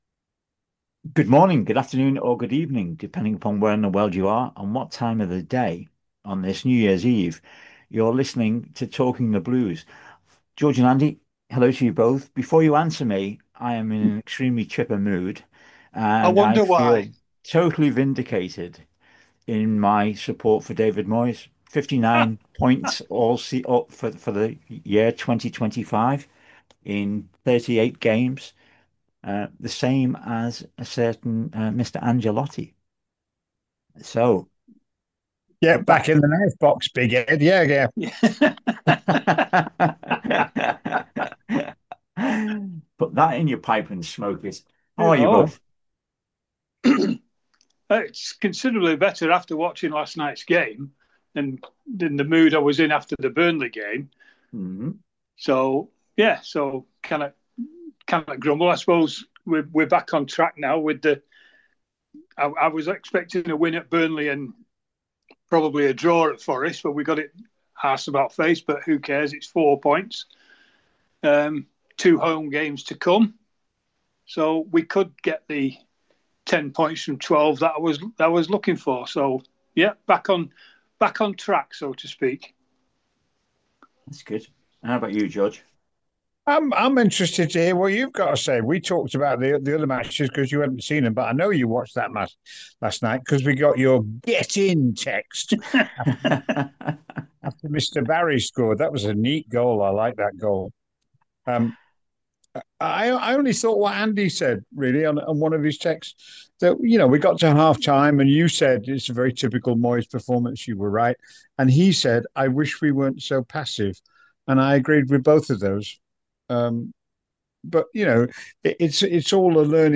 Three passionate Everton supporters discuss the Blues. Weekly podcast discussing both on and off field matters. Usually with a bit of humour thrown in!